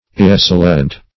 Search Result for " irresilient" : The Collaborative International Dictionary of English v.0.48: Irresilient \Ir`re*sil"i*ent\, a. Not resilient; not recoiling or rebounding; inelastic.